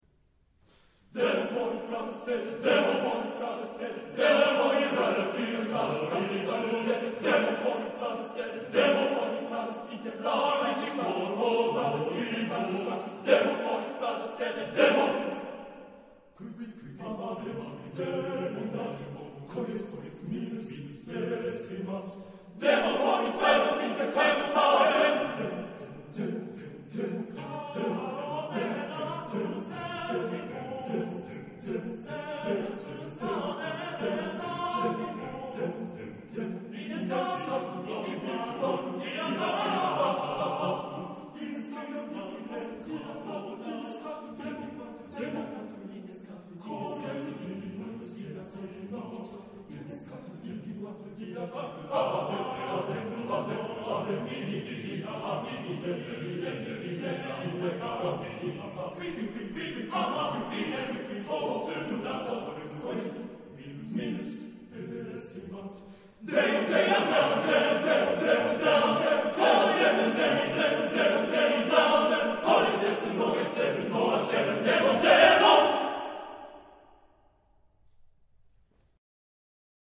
Genre-Style-Form: Sacred
Mood of the piece: fast
Type of Choir: TTBarB  (4 men voices )
Tonality: various
Discographic ref. : Internationaler Kammerchor Wettbewerb Marktoberdorf